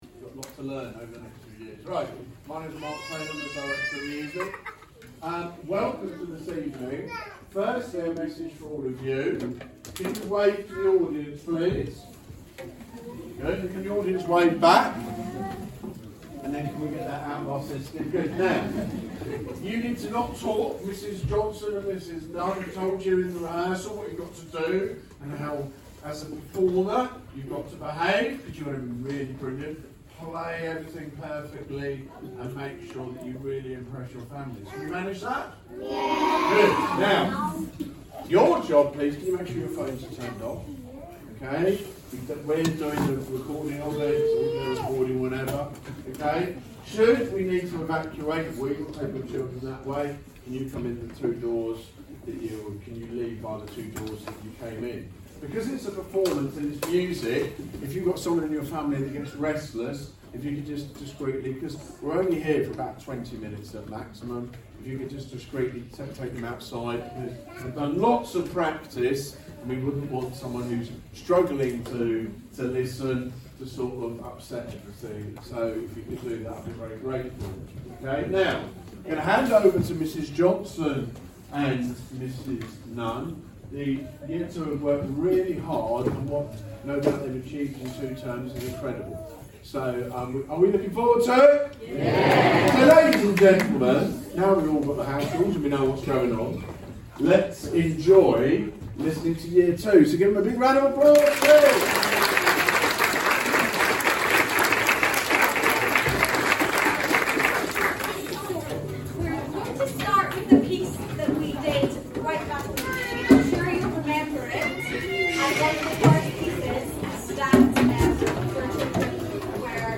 Year 2 Strings Celebration | March 2026